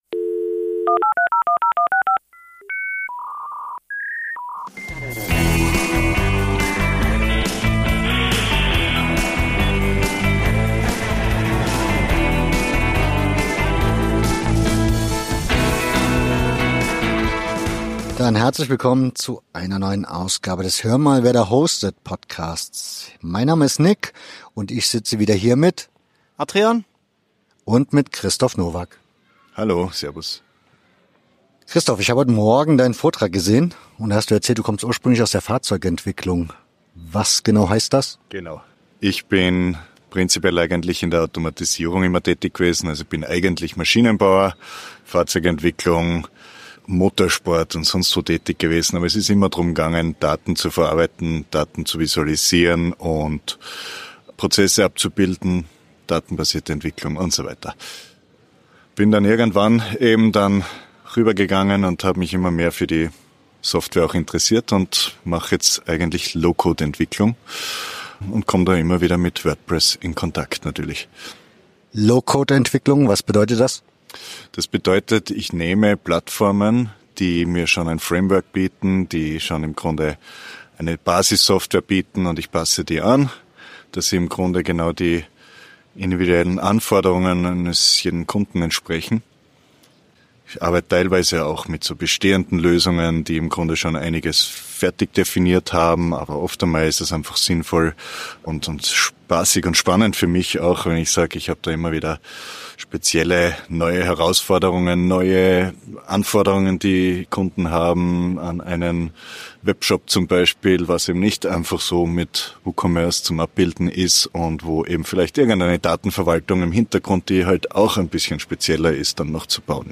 Grund genug für eine Unterhaltung.